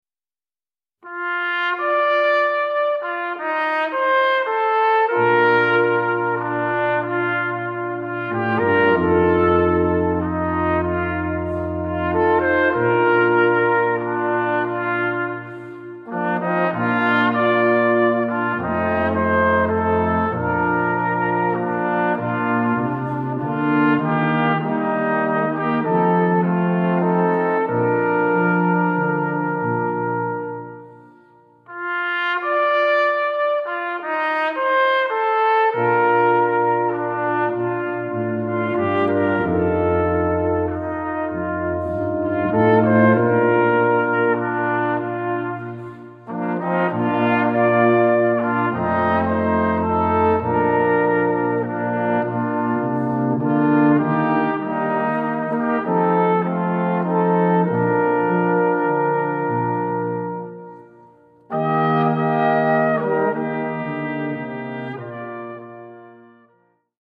Gattung: für variables Blechquartett
Besetzung: Ensemblemusik für 4 Blechbläser